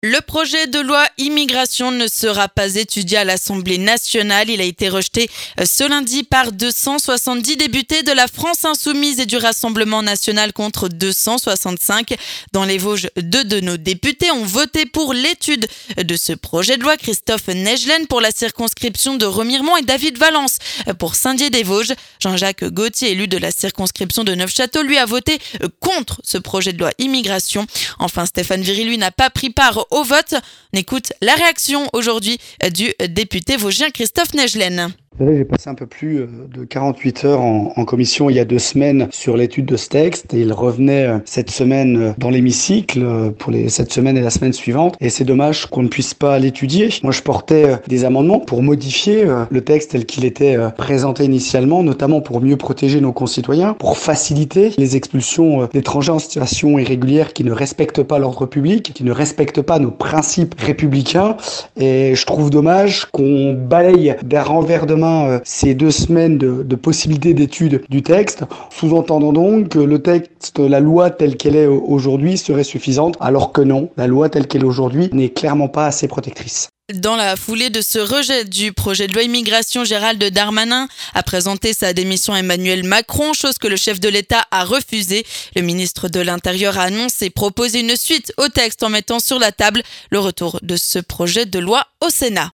La réaction de Christophe Naegelen, député pour la circonscription de Remiremont, suite au rejet du projet de loi immigration à l'Assemblée nationale.